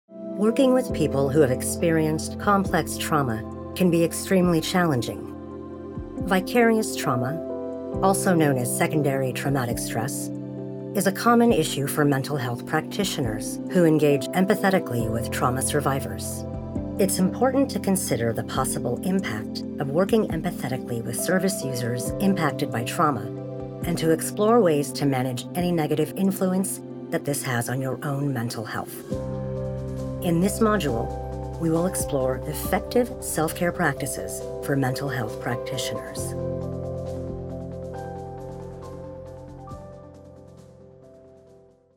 E-Learning
I have a naturally rich, deep voice that exudes confidence while maintaining authenticity and relatability.